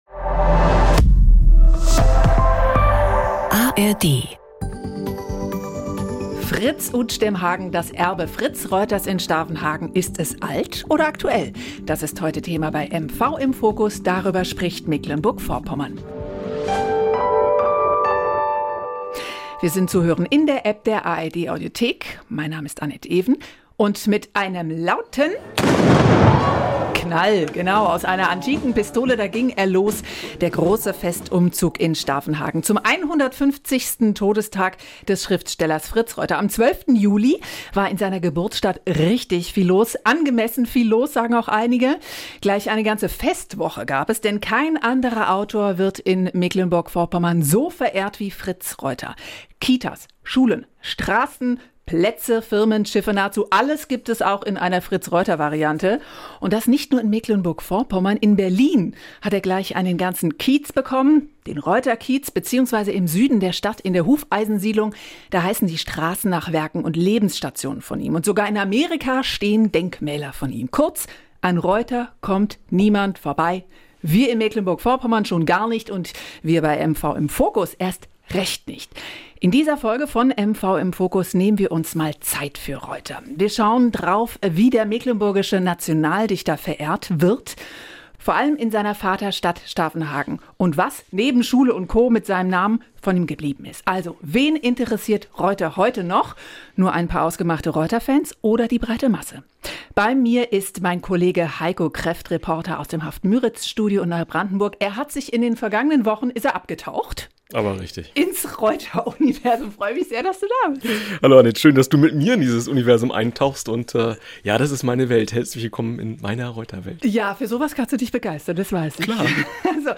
Fritz Reuter ist in diesem Jahr noch präsenter, als ohnehin schon. Wir berichten aus Stavenhagen.